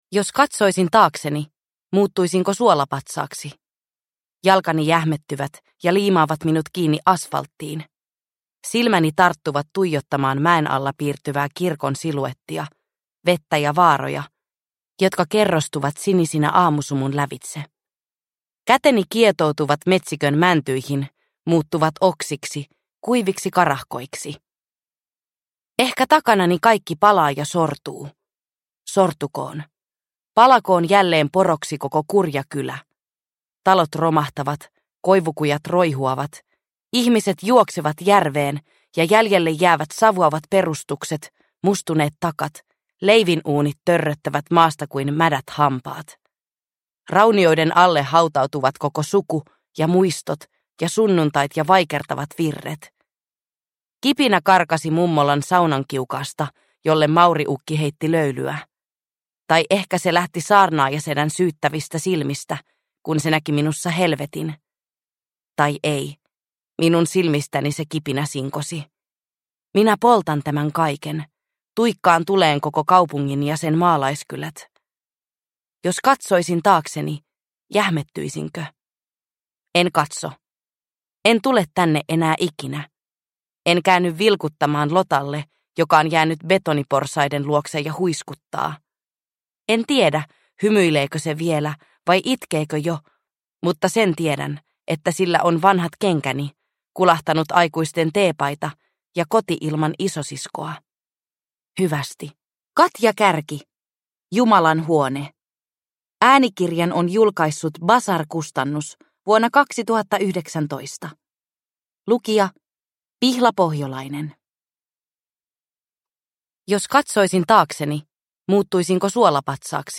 Jumalan huone – Ljudbok – Laddas ner